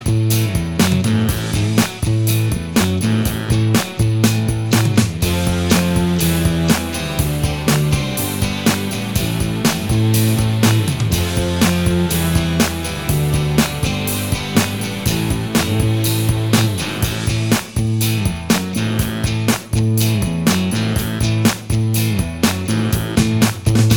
Minus Guitars Glam Rock 3:31 Buy £1.50